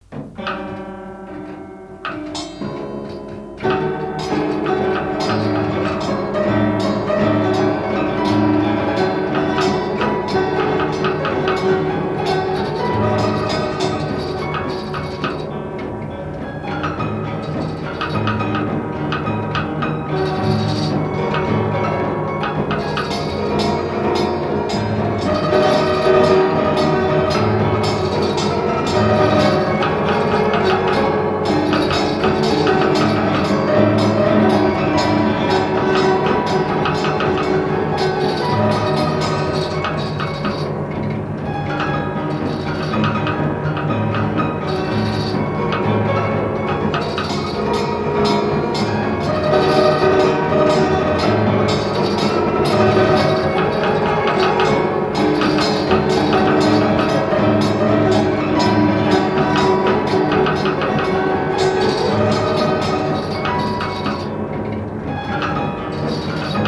danse : java
Pièce musicale inédite